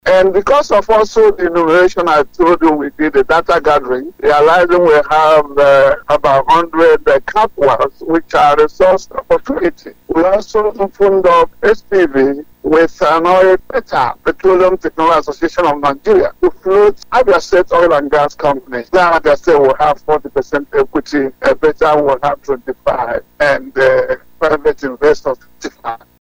Speaking on Family Love FM’s Open Parliament, monitored by dailytrailnews, the Commissioner for Petroleum and Solid Minerals Prof. Joel Ogbonna maintained that the project is on course and is being pursued with all seriousness and commitment by parties concerned.